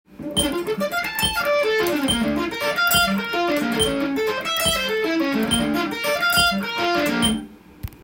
スィープピッキングtab譜
①のフレーズはkeyがDmで使えるものになっています。
全て３連符になっていますので最初はメトロノームに合わせて